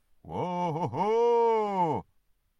Звуки Санта-Клауса